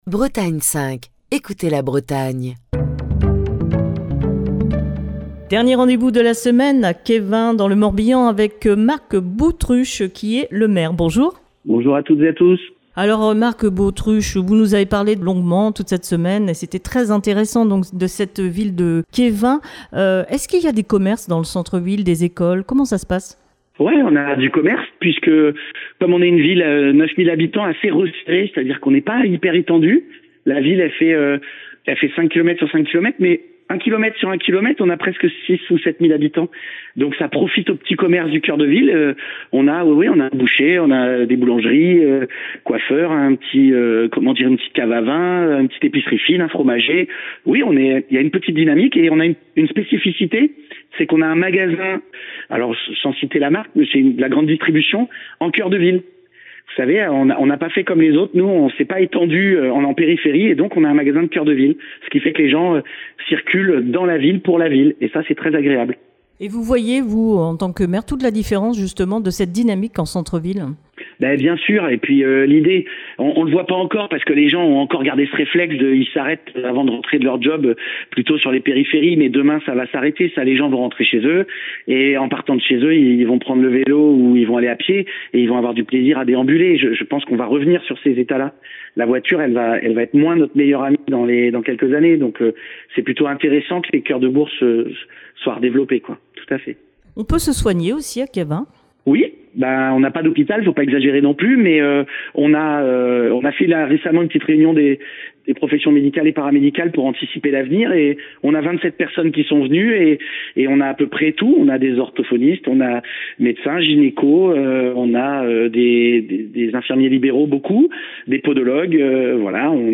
Dernière escale à Quéven dans le Morbihan où Destination commune a posé ses micros cette semaine.